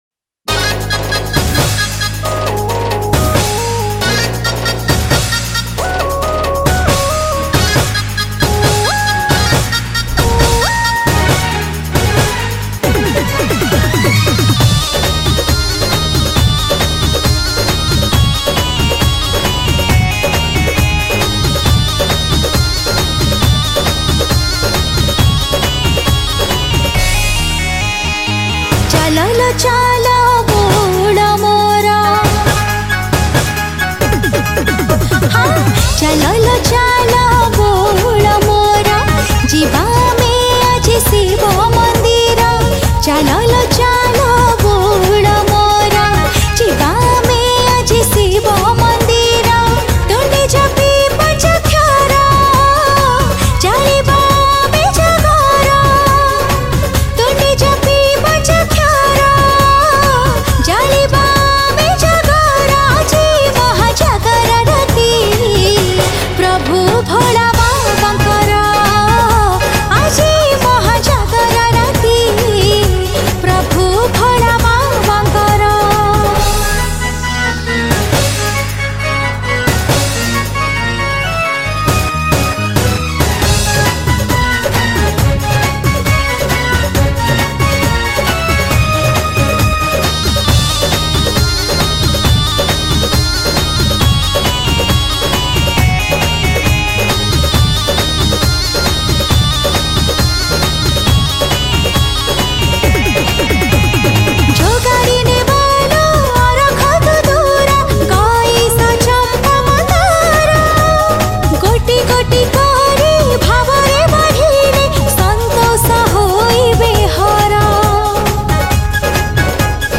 Jagara Special Odia Bhajan Song 2022 Songs Download